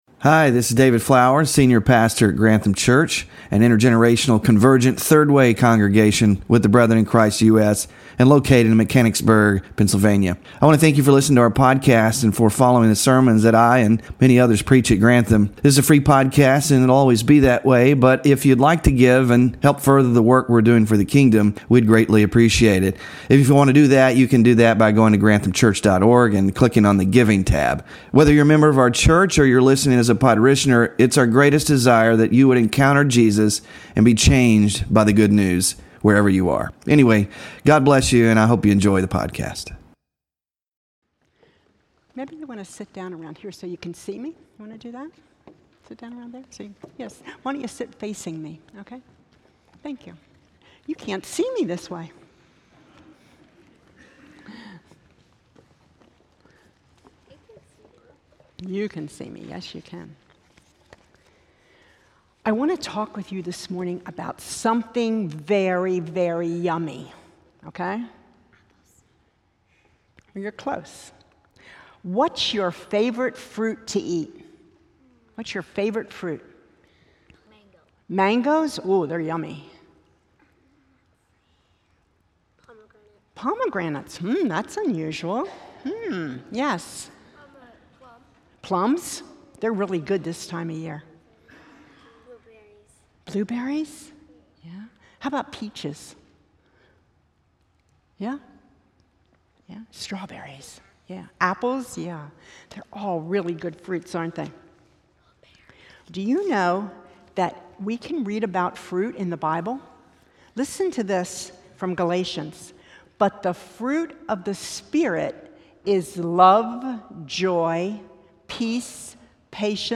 THE FRUIT OF THE SPIRIT WK7- FAITHFULNESS SERMON SLIDES